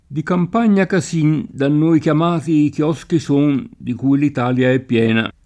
chiosco [kL0Sko] s. m. («padiglione; edicola»); pl. ‑schi — già in uso fino ai primi del ’900 anche la gf. kiosco [id.], riferita solo a usanze orientali: Di campagna casin da noi chiamati I Kioschi son, di cui l’Italia è piena [